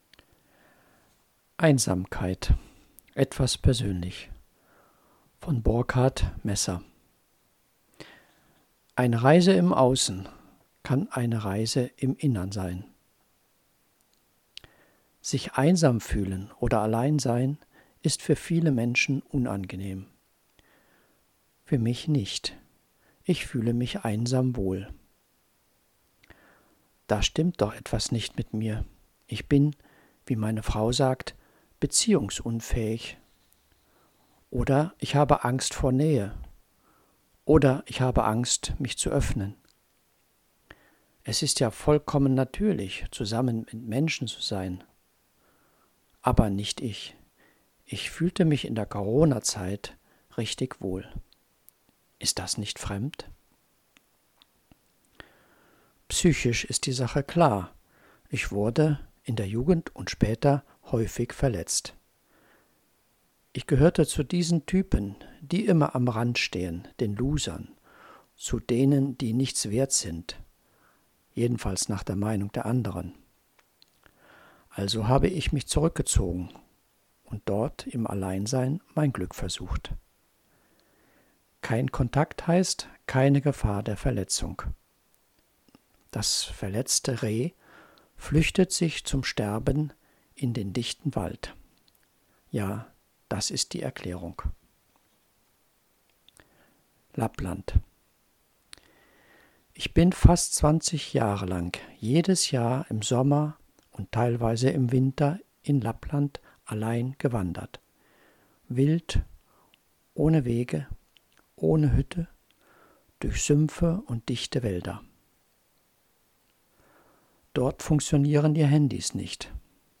Artikel vorgelesen